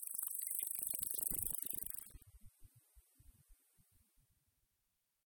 rodretract2.ogg